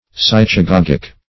Search Result for " psychagogic" : The Collaborative International Dictionary of English v.0.48: Psychagogic \Psy`cha*gog"ic\, a. [Gr.
psychagogic.mp3